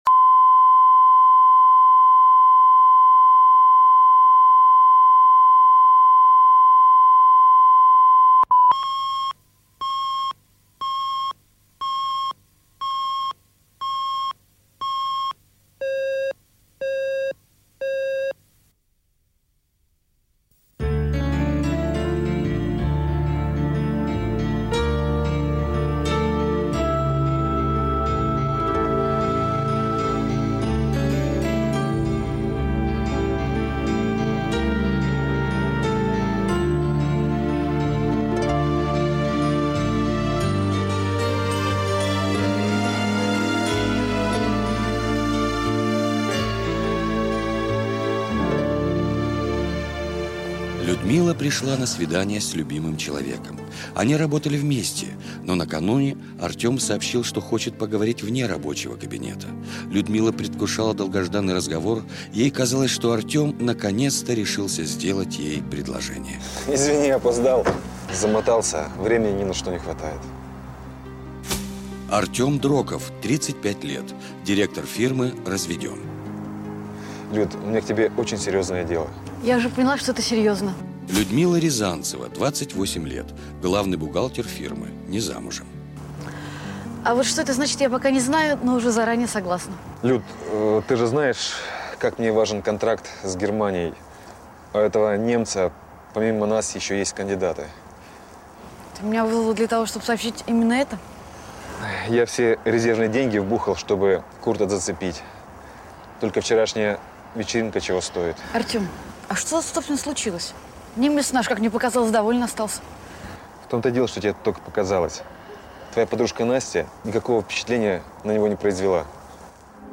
Аудиокнига Любовь по контракту | Библиотека аудиокниг